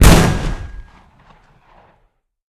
shotgunAlt.ogg